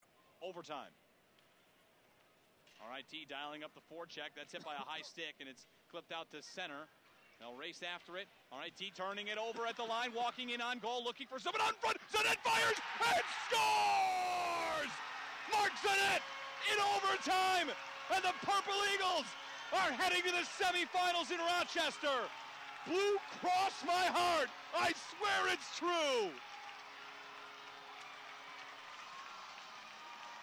Game-Winning Call